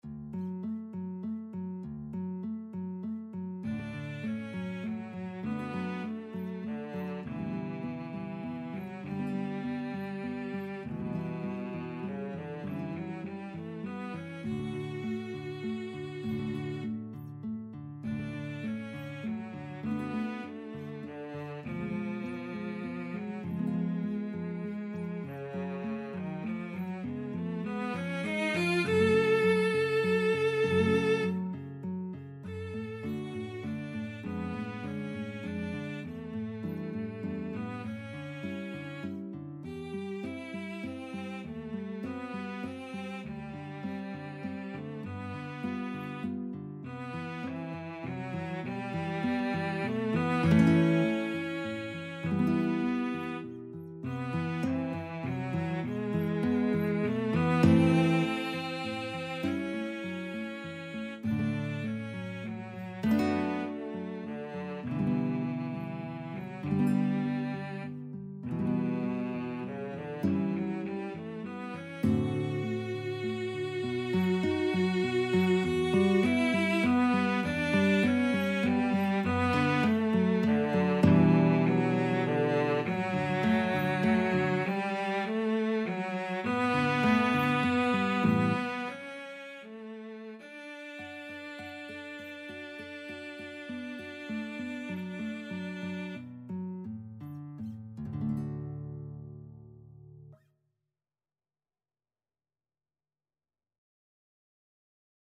3/4 (View more 3/4 Music)
Cantabile
Classical (View more Classical Guitar-Cello Duet Music)